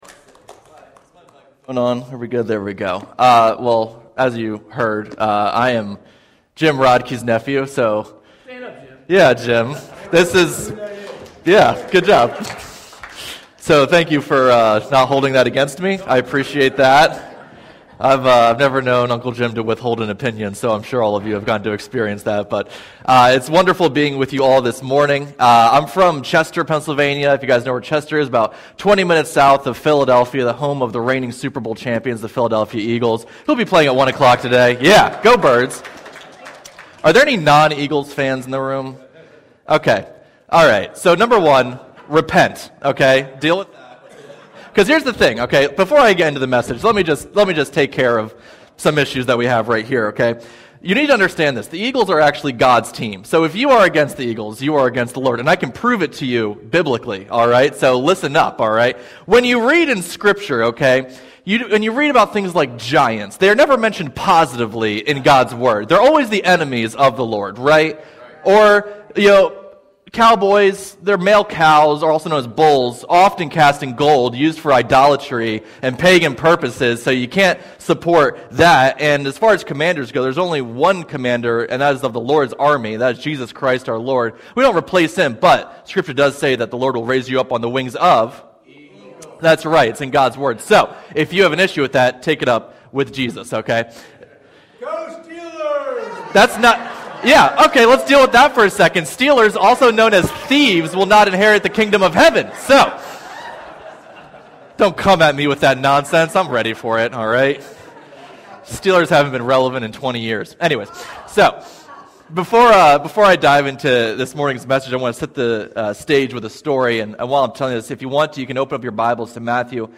YBC Worship Service
Click on image to view Service Sermon Audio